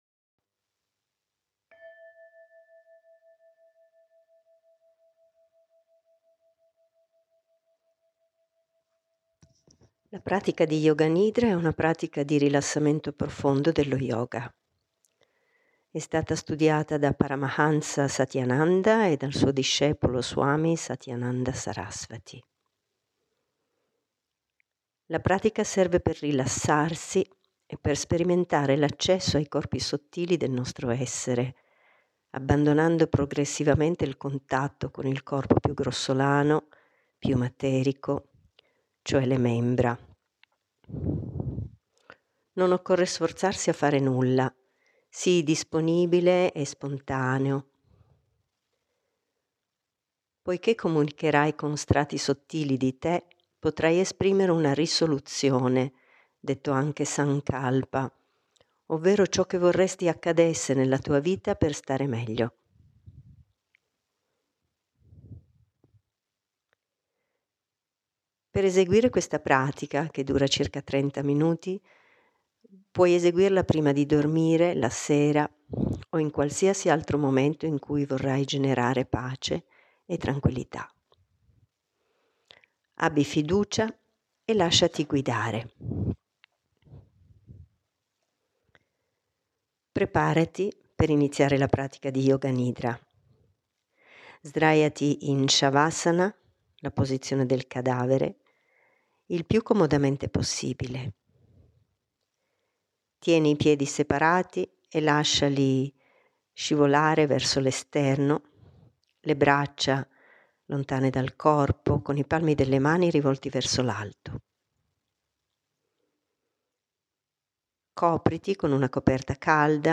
Pratica di Yoga Nidra. Rilassamento profondo. Ascoltala prima di addormentarti.